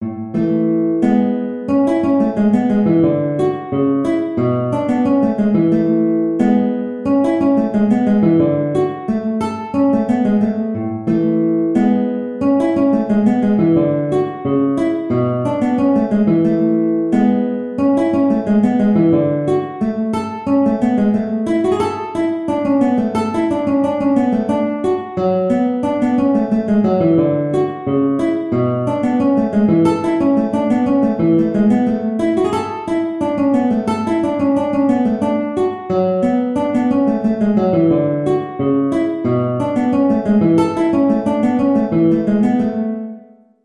A major (Sounding Pitch) (View more A major Music for Guitar )
2/4 (View more 2/4 Music)
A3-A5
Guitar  (View more Intermediate Guitar Music)
Traditional (View more Traditional Guitar Music)
Scottish